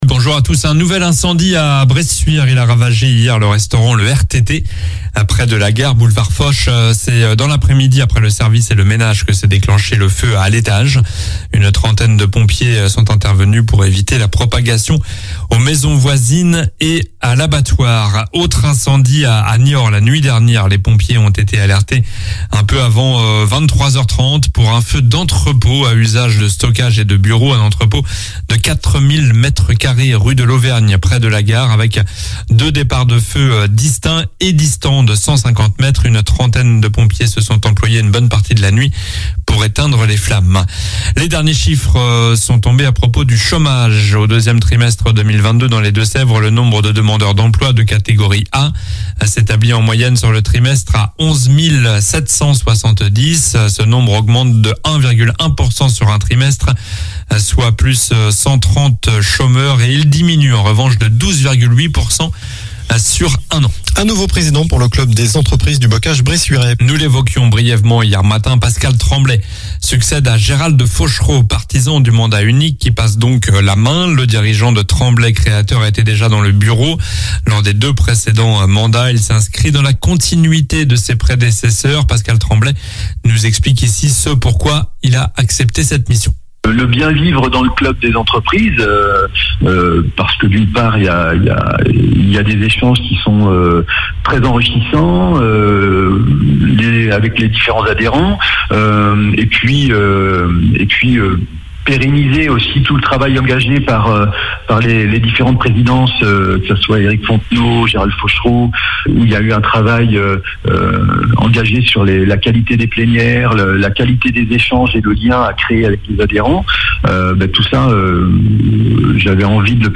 Journal du jeudi 27 juillet (matin)